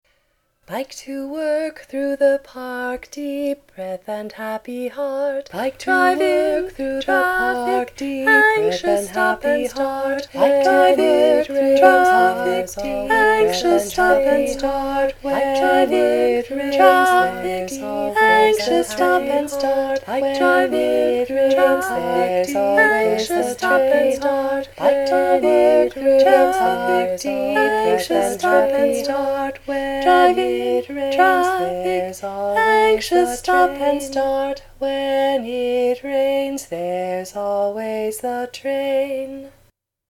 Do you know this round?
NOTE: This was an experiment in singing a round with myself. It turned out to be harder than I’d imagined, and the result proves my theory that a variety of voices make for better harmony.
BikingRound.mp3